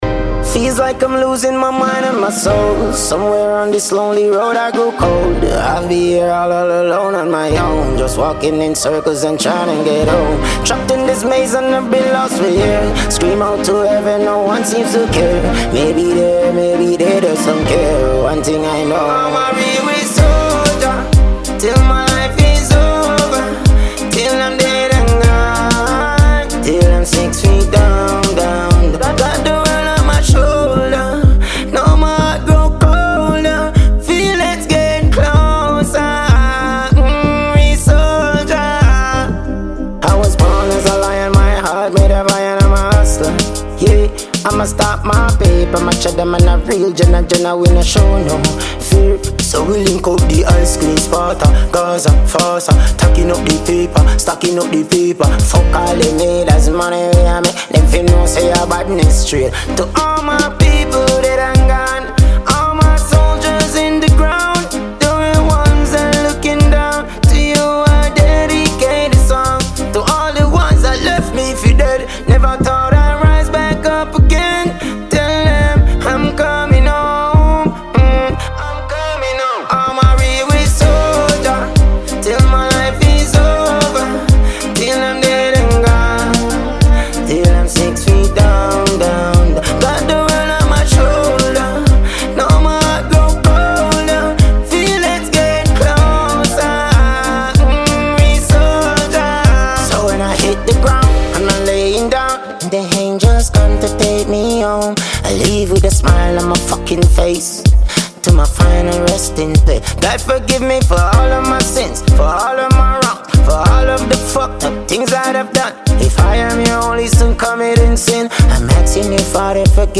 Dancehall Music